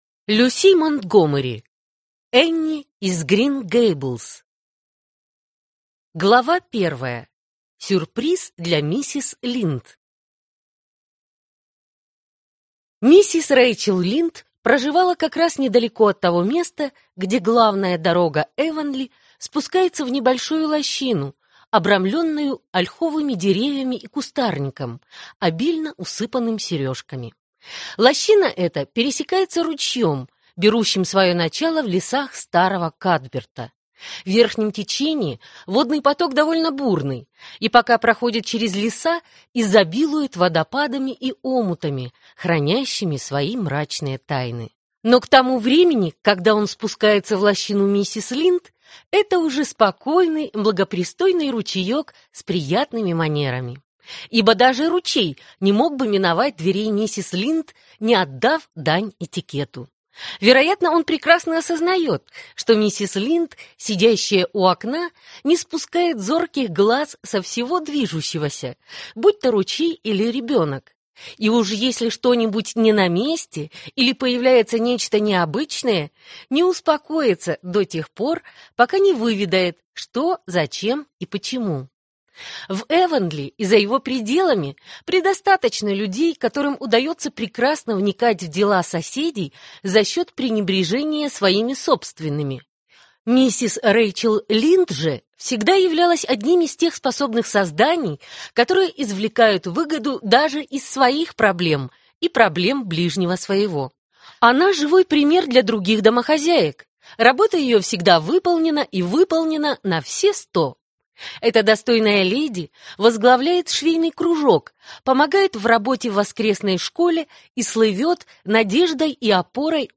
Аудиокнига Энни из Грин-Гейблз | Библиотека аудиокниг